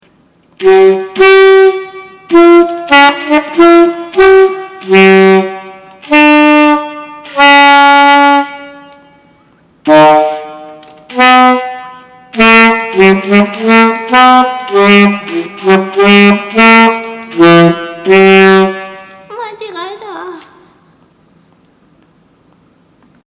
初めてここまで吹けたので感動して録音。高いキーと低いキーのところって大変なんだから。